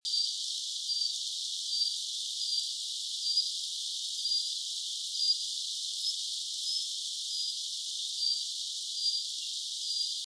34-1杉林溪1008小剪尾1-1.mp3
小剪尾 Enicurus scouleri fortis
南投縣 竹山鎮 杉林溪
錄音環境 溪邊
錄音: 廠牌 Denon Portable IC Recorder 型號 DN-F20R 收音: 廠牌 Sennheiser 型號 ME 67